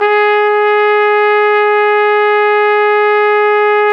Index of /90_sSampleCDs/Roland L-CD702/VOL-2/BRS_Flugelhorn/BRS_Flugelhorn 2